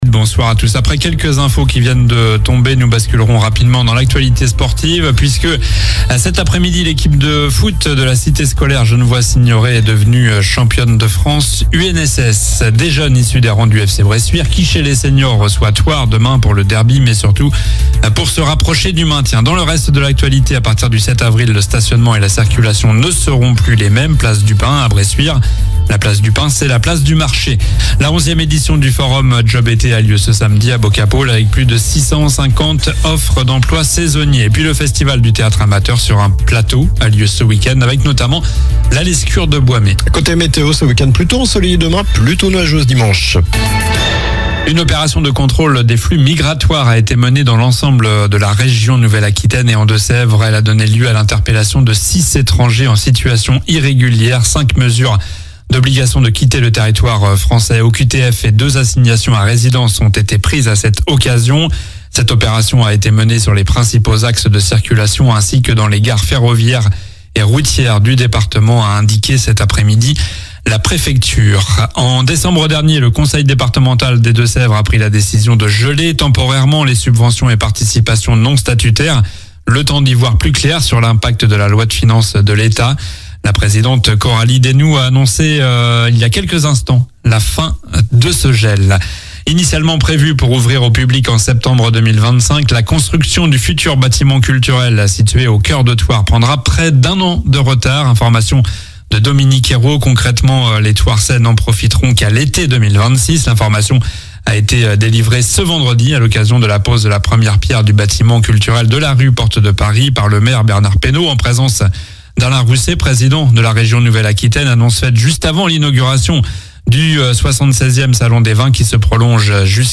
Journal du vendredi 28 mars (soir)